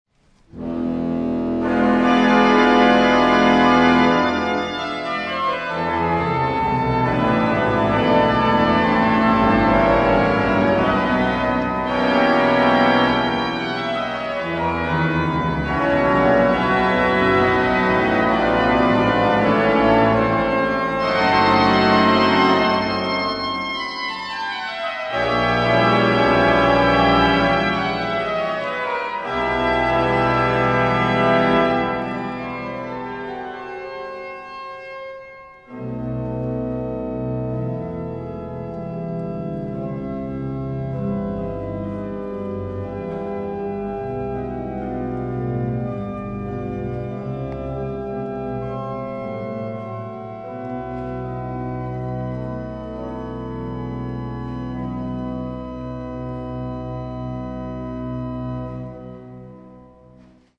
Concert sur l'orgue Aubertin de l'église Saint-Louis à Vichy
Les extraits montrent quelques échantillons des sonorités particulières de l'orgue.